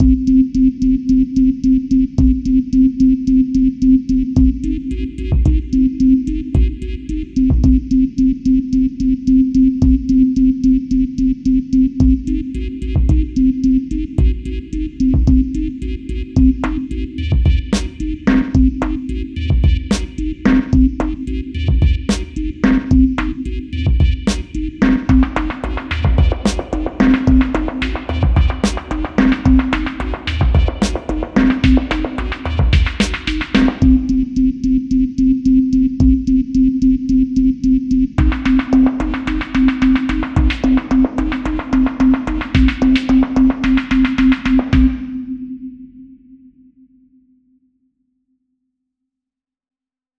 Pieza Intelligent dance music (IDM)
Música electrónica
Dance
melodía
sintetizador